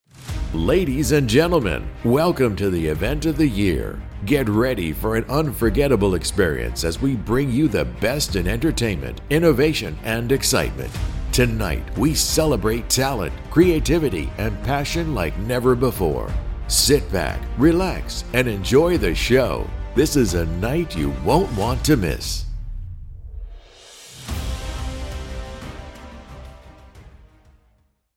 Professional Voice Artist
English - USA and Canada
Middle Aged
Senior